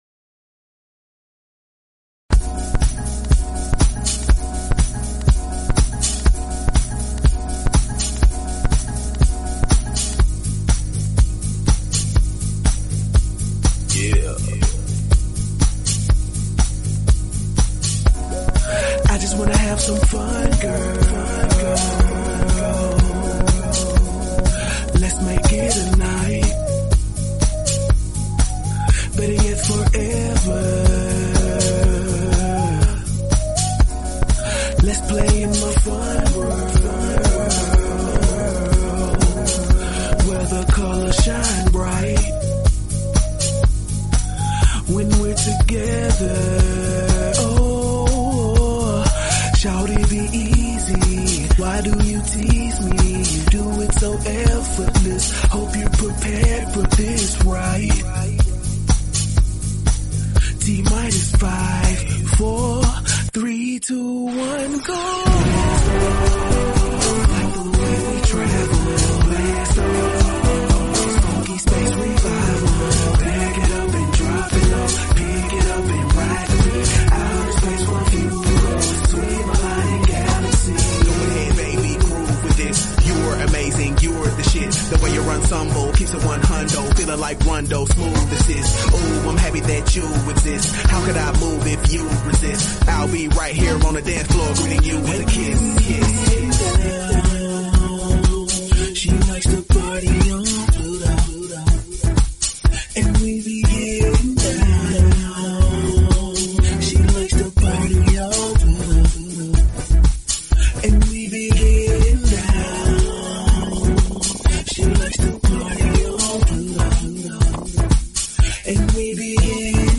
Talk Show Episode, Audio Podcast, Todays_Entrepreneurs and Courtesy of BBS Radio on , show guests , about , categorized as
During each broadcast, there will be in-depth guest interviews discussing the problems and advantages business owners face. Topics will include sales and marketing, branding, interviewing, and much more.
In addition to daily guests, TE spins the hottest indie musical artists and laughs along to the funniest comedians every day.